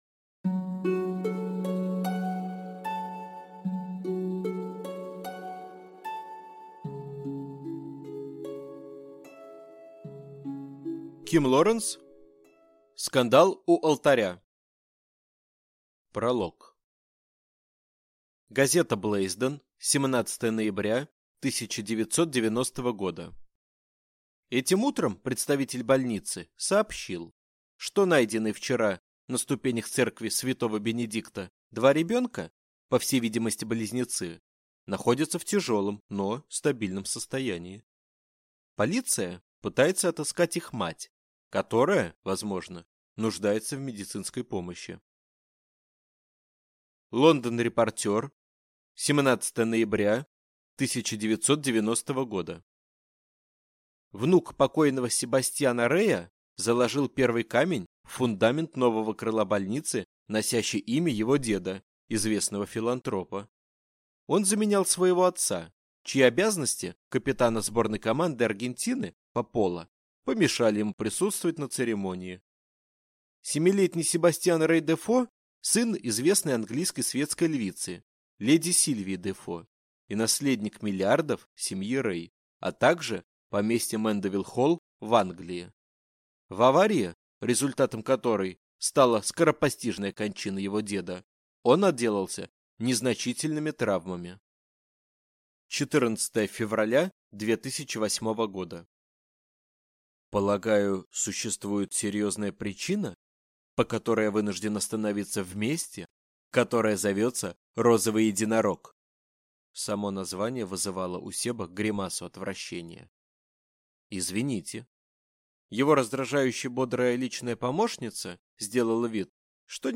Аудиокнига Скандал у алтаря | Библиотека аудиокниг